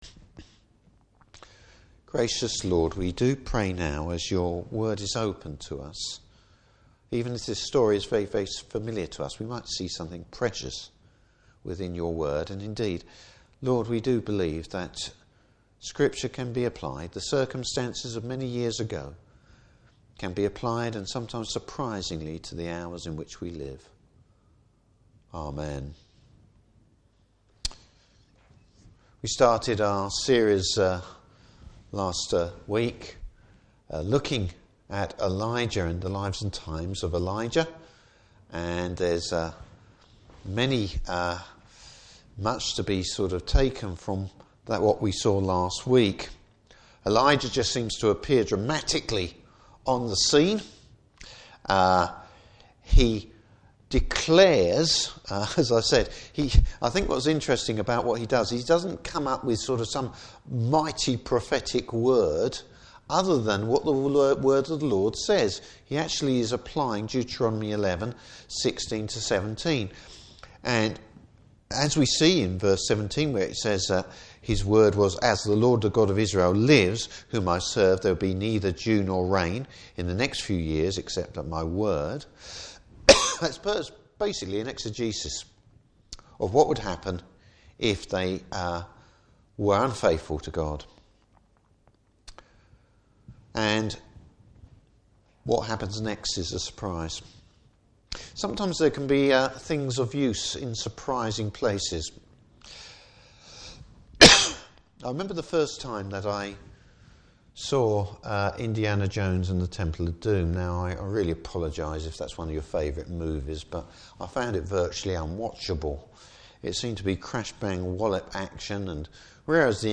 Service Type: Evening Service Bible Text: I Kings 17:2-24.